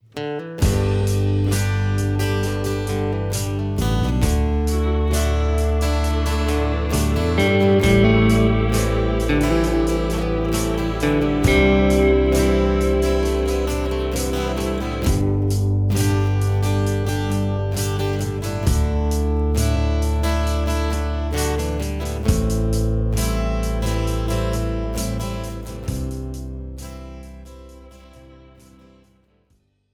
This is an instrumental backing track cover.
• Key – G
• Without Backing Vocals
• No Fade